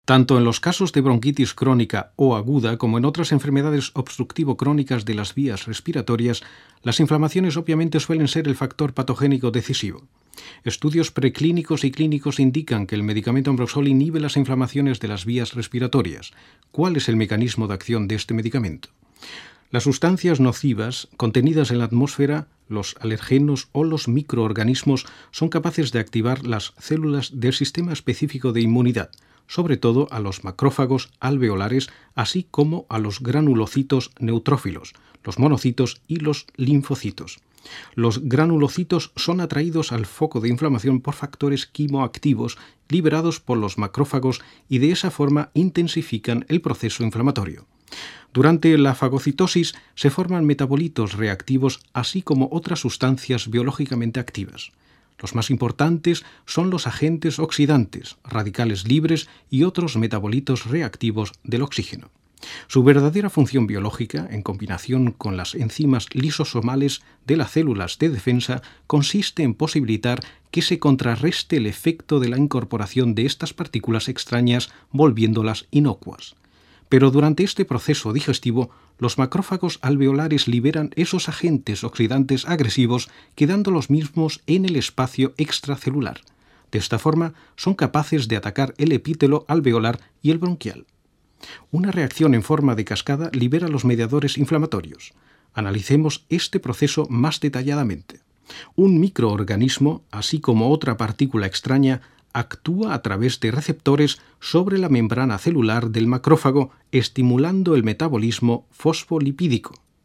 Kein Dialekt
Sprechprobe: Werbung (Muttersprache):
spanish voice over talent